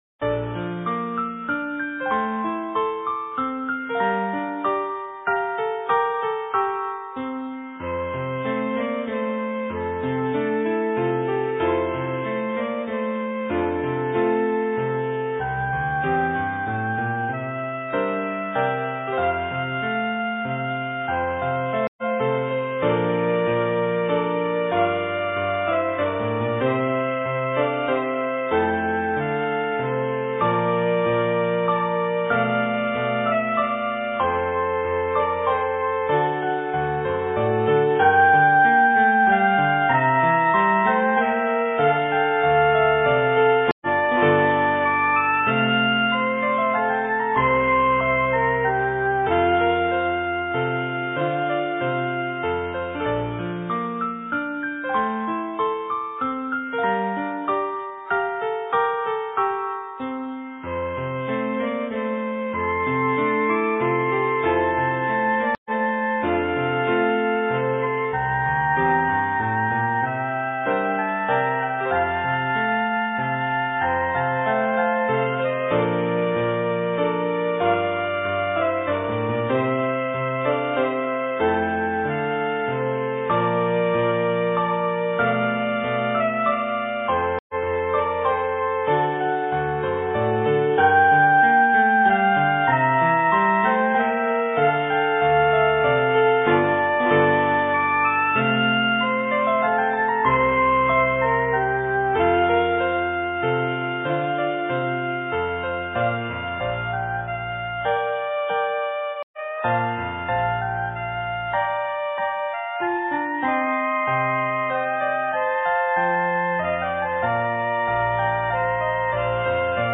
Duet: fl; cl; sax